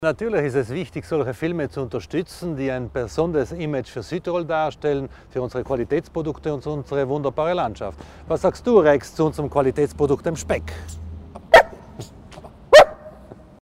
Landesrat Thomas Widmann zur Bedeutung für Südtirol ein wichtiger Filmstandort zu sein